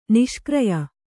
♪ niṣkraya